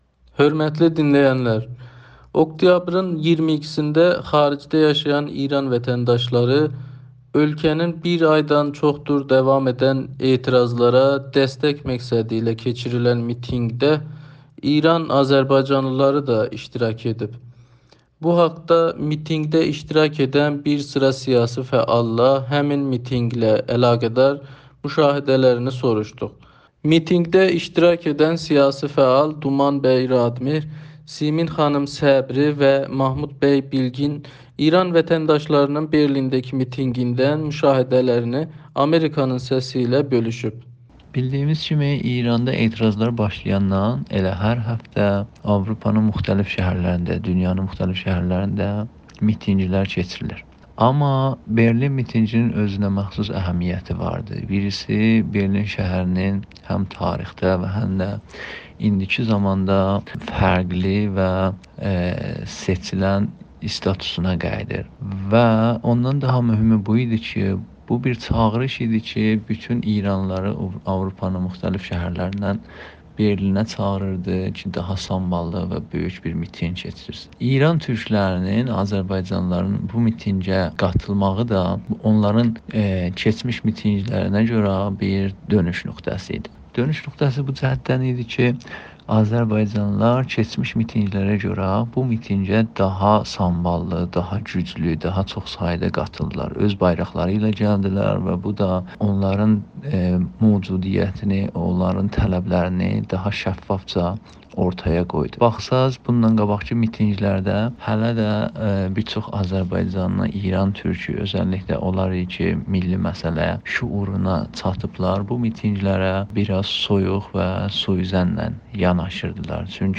Berlin mitinqi ilə əlaqədar müsahibə.mp3